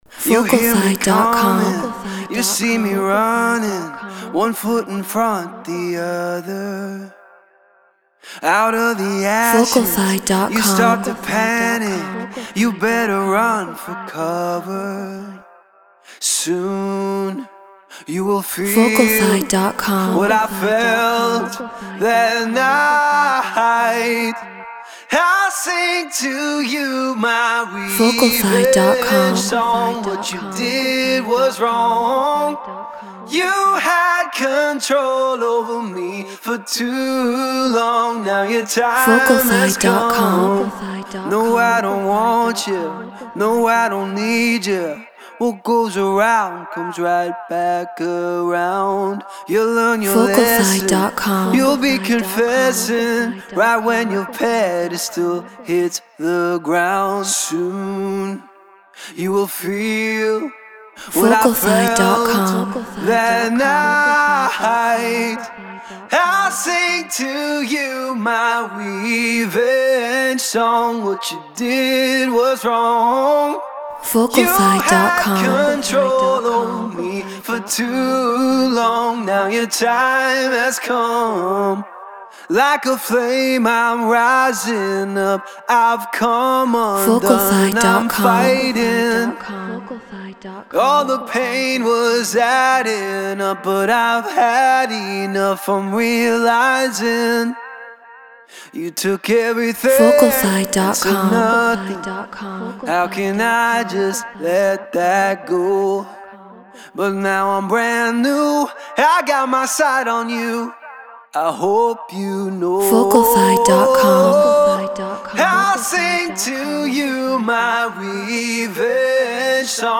Get Royalty Free Vocals.
Non-Exclusive Vocal.